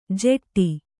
♪ jeṭṭi